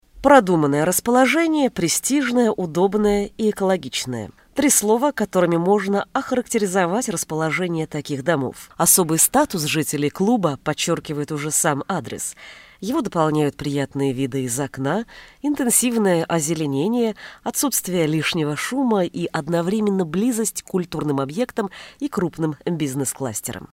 Микрофон Samson C01U PRO, условия для записи есть.
Демо-запись №1 Скачать